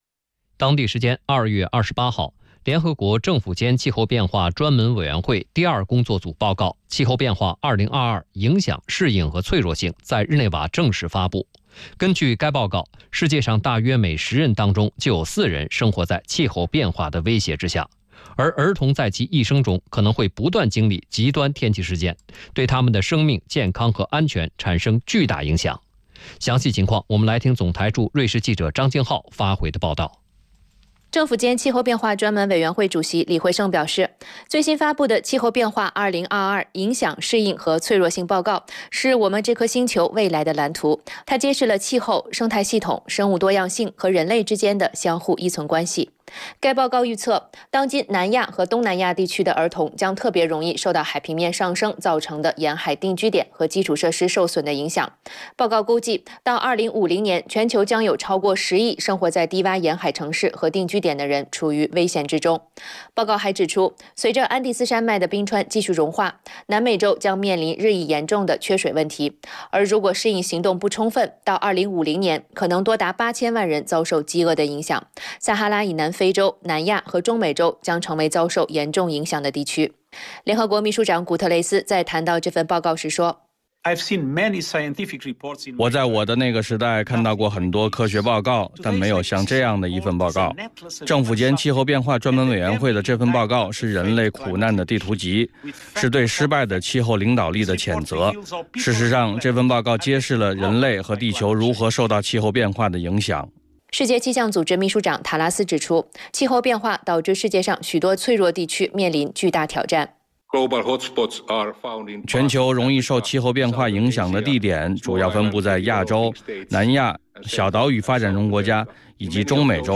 以下为采访音频。